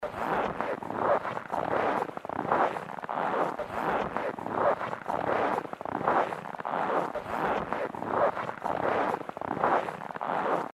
Звуки зимы, снега
Погрузитесь в атмосферу зимы с нашей подборкой звуков: хруст снега, завывание вьюги, мягкое падение снежинок.